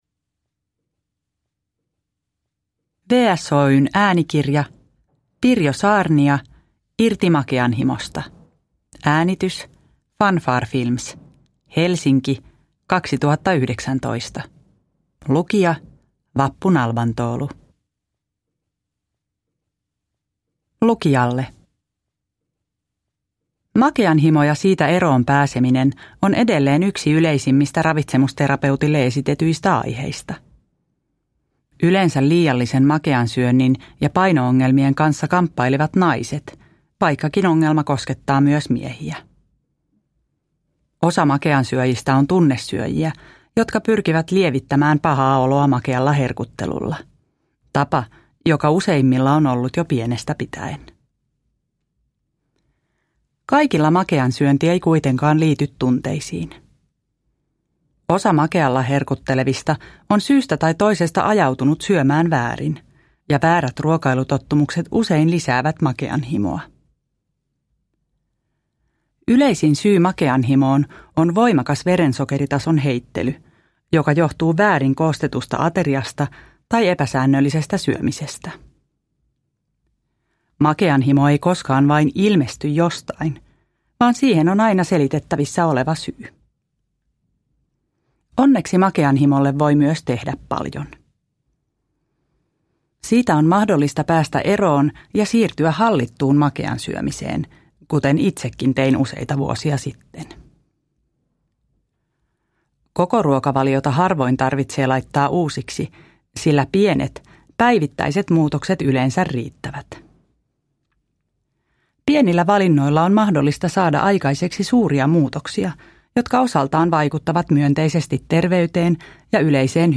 Irti makeanhimosta – Ljudbok – Laddas ner